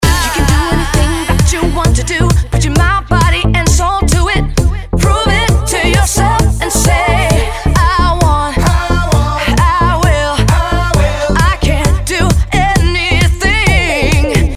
Эта, всё-таки Voyager - это новый синт с новыми фичами и звуком, а не перевыпущенный Model D. Вложения bass.mp3 bass.mp3 342,8 KB · Просмотры: 727